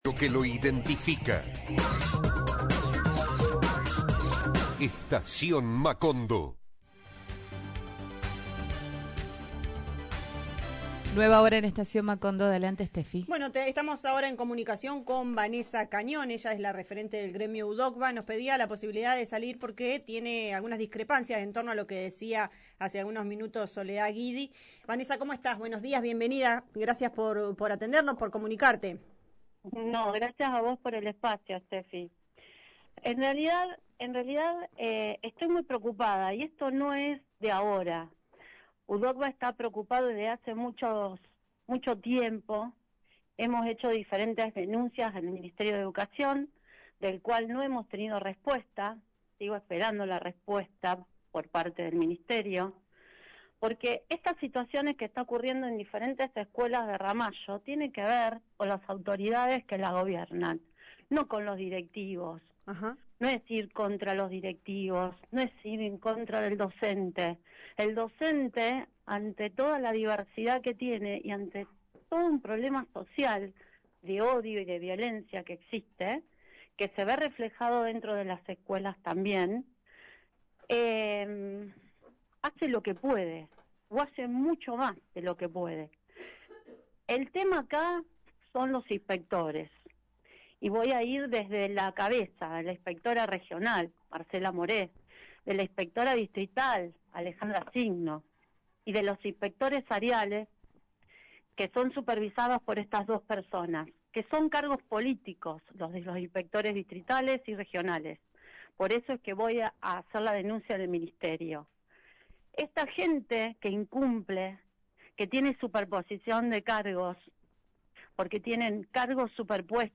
En diálogo con Estación Macondo por Radio Ramallo, aseguró que “las irregularidades se encubren desde hace años” y anticipó nuevas denuncias ante el Ministerio de Educación.